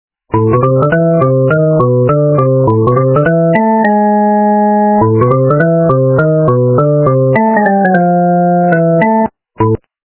полифоническую мелодию